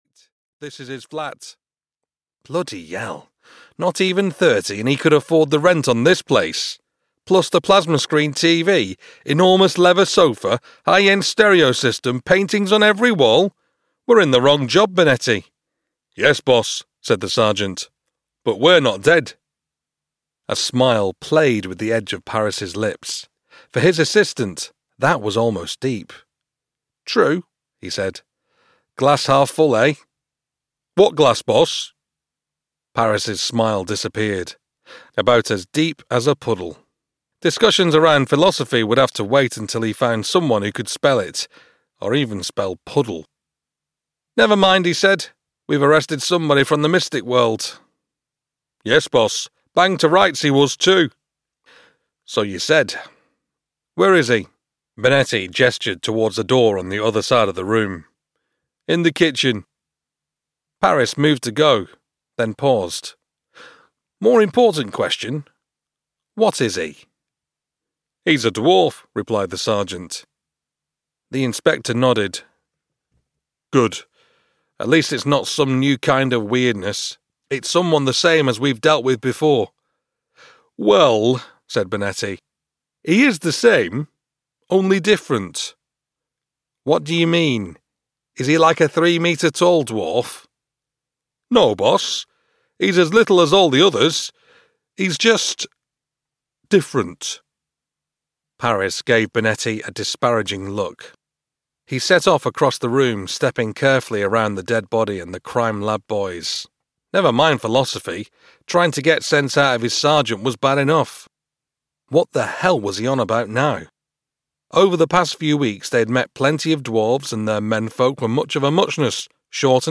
Know Your Rites (EN) audiokniha
Ukázka z knihy